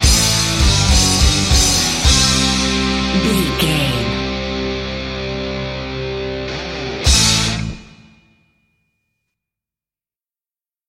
Ionian/Major
drums
electric guitar
bass guitar
Sports Rock
hard rock
lead guitar
aggressive
energetic
intense
nu metal
alternative metal